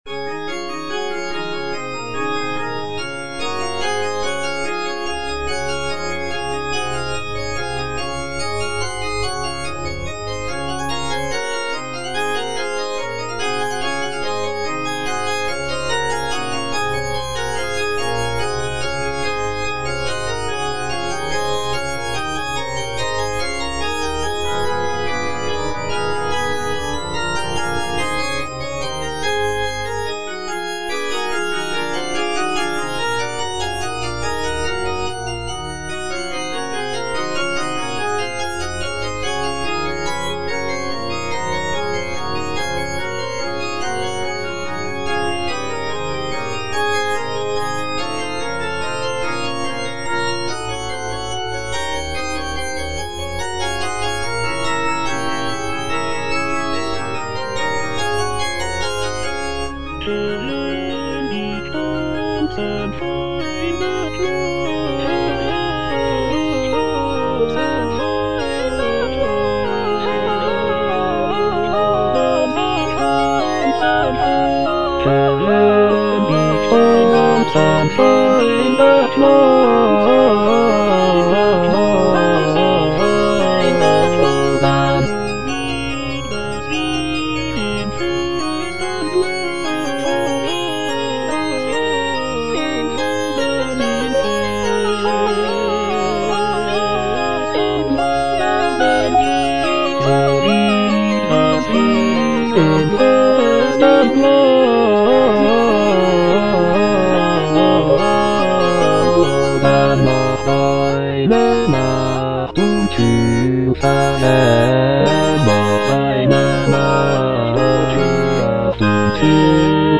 J.S. BACH - CHRISTMAS ORATORIO BWV248 - CANTATA NR. 6 (A = 415 Hz) 54 - Herr, wenn die stolzen Feinde schnauben - Bass (Emphasised voice and other voices) Ads stop: auto-stop